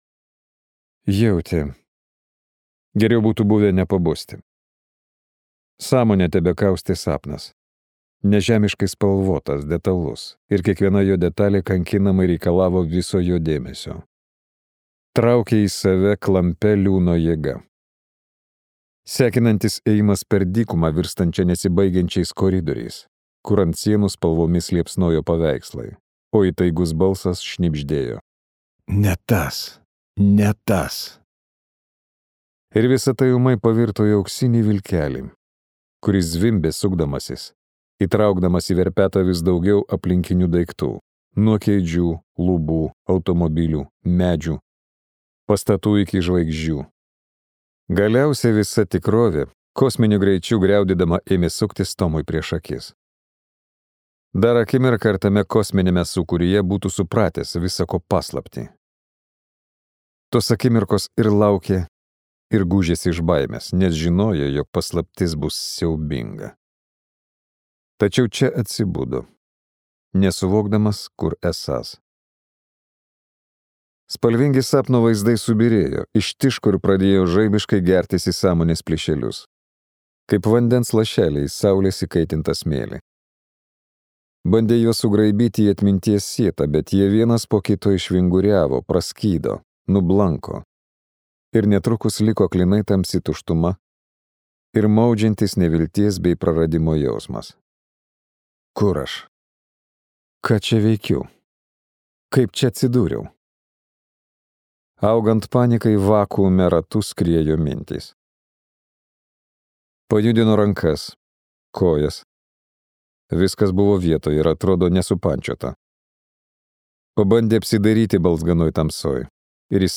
Moneta & labirintas II | Audioknygos | baltos lankos